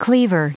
Prononciation du mot cleaver en anglais (fichier audio)
Prononciation du mot : cleaver
cleaver.wav